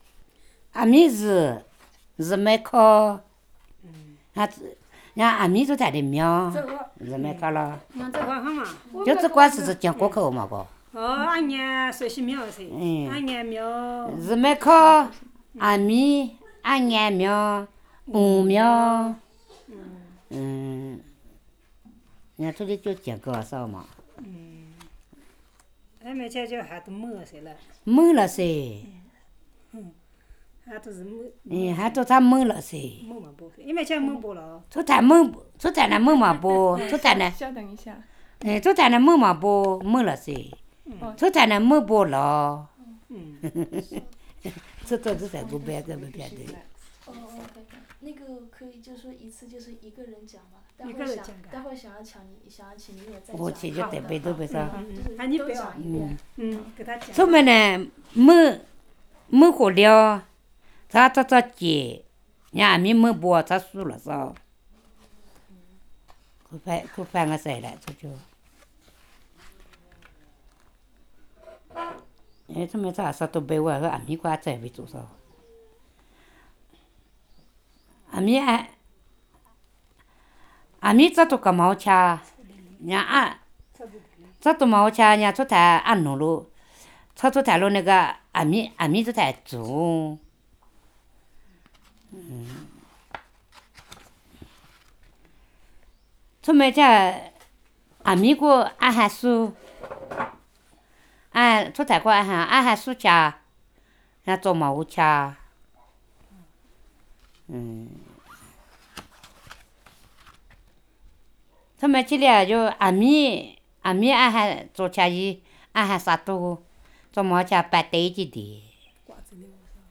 digital wav file recorded at 44kHz/16 bit on Zoom H4n solid state recorder
China, Yunnan Province, Chuxiong Yi Autonomous Prefecture, Wuding County, Gubai Administration, Shanju Dacun Village